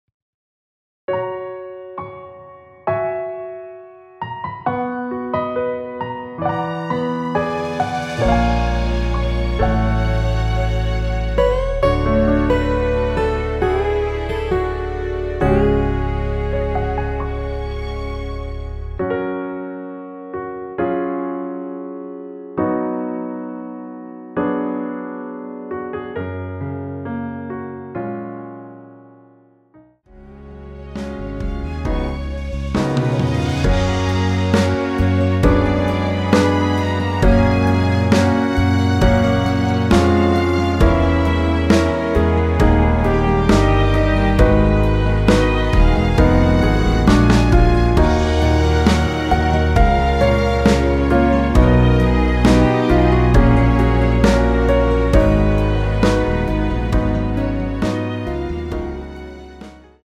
원키에서(-1)내린 MR입니다.
Gb
앞부분30초, 뒷부분30초씩 편집해서 올려 드리고 있습니다.
중간에 음이 끈어지고 다시 나오는 이유는